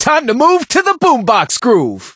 brock_start_vo_04.ogg